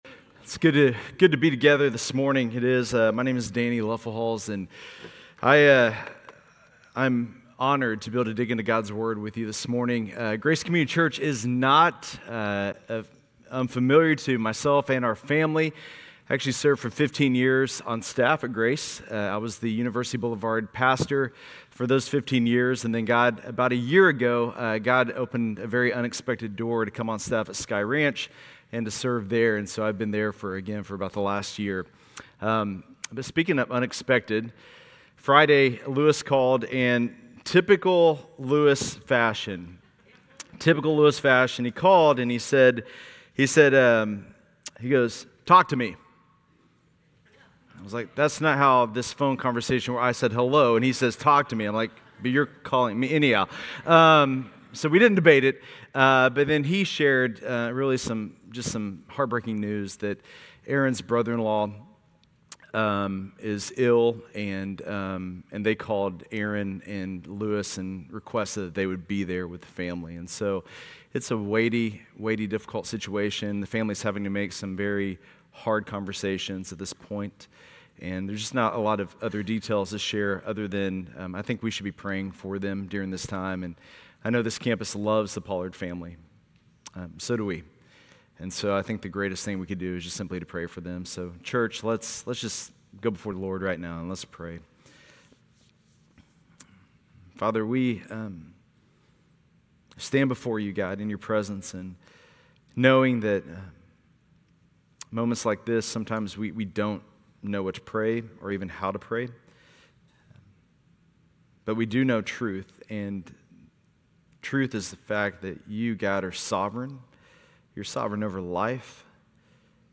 Grace Community Church Lindale Campus Sermons Galatians 1:1-24 Apr 08 2024 | 00:37:17 1x 00:00 / 00:37:17 Subscribe Share RSS Feed Share Link Embed